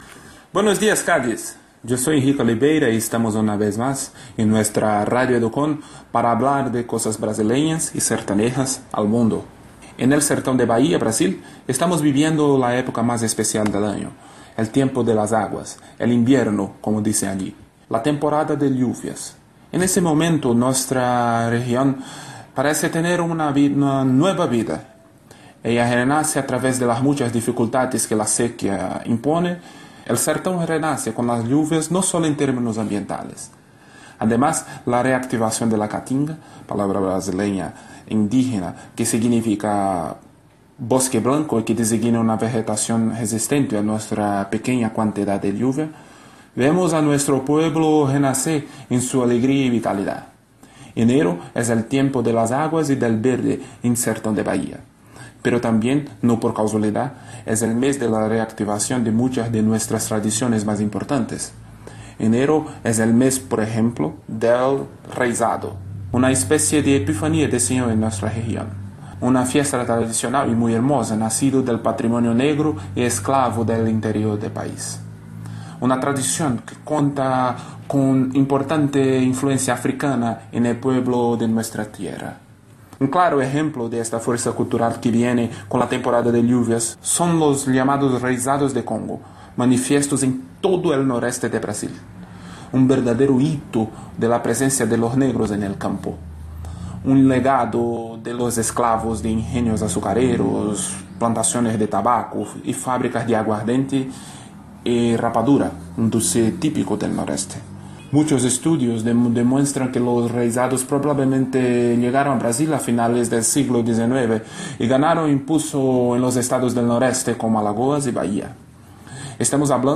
blog hablado